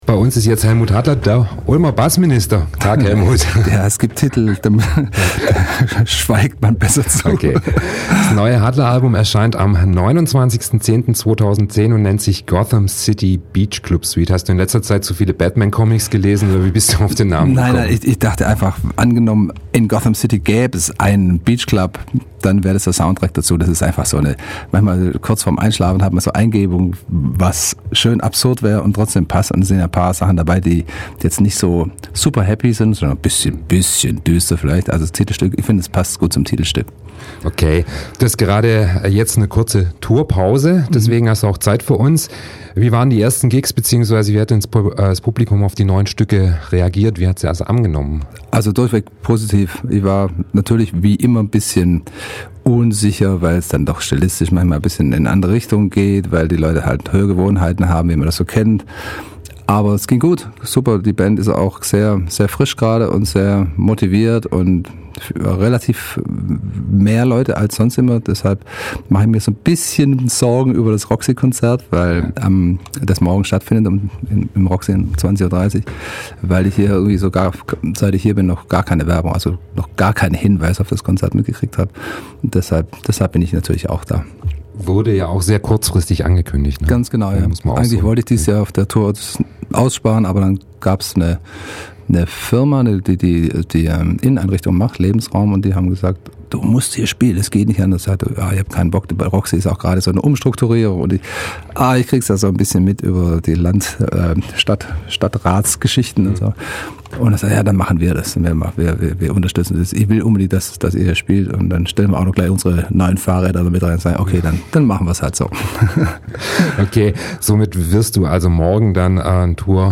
Am 13.10. war Hellmut Hattler bei uns zu Gast in der Ulmer Freiheit.
hattler_interview_ulmer_fre.mp3